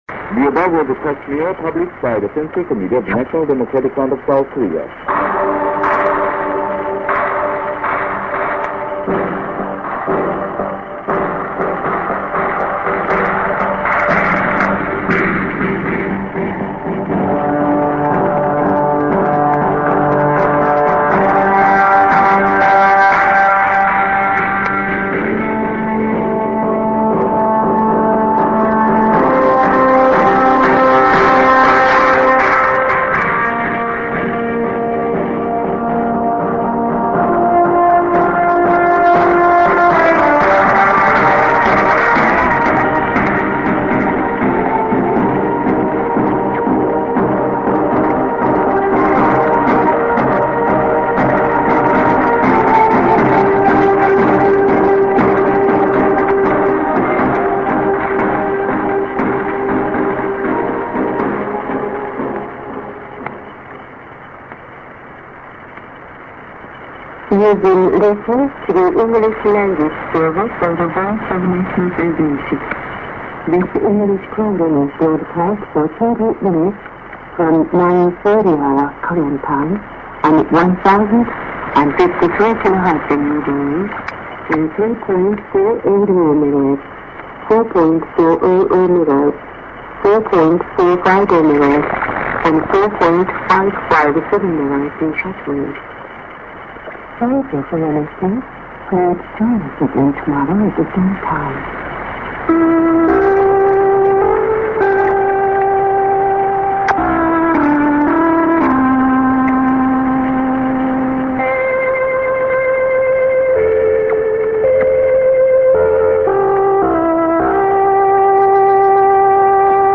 a:　Eng.End ->ID+SKJ(women)->IS->